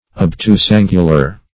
Obtusangular \Ob*tus"an`gu*lar\, a.
obtusangular.mp3